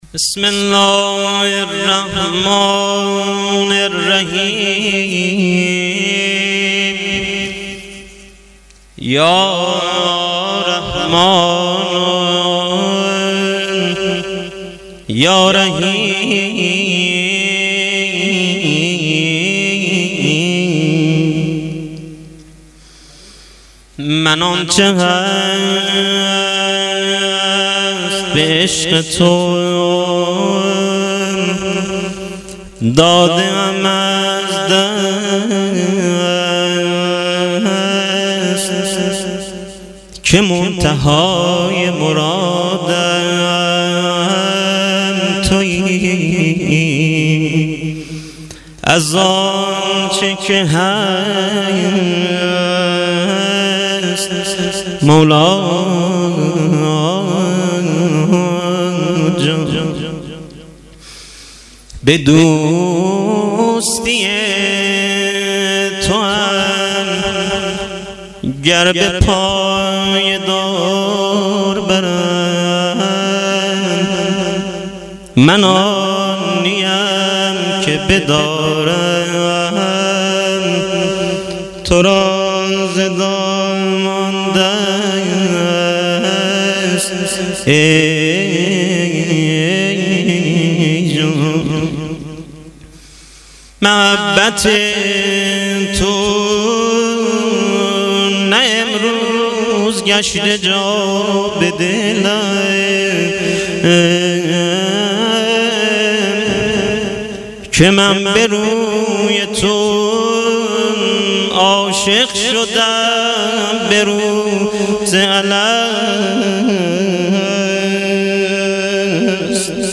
مناجات و روضه امام حسین (پیشواز رمضان)